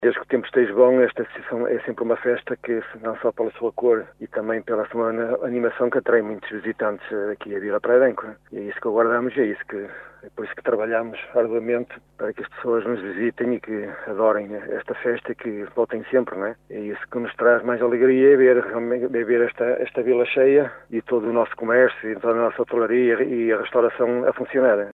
Carlos Castro, presidente da junta de freguesia de Vila Praia de Âncora, está no local a ultimar os preparativos desta grande festa, como revelou em entrevista ao Jornal C – O Caminhense.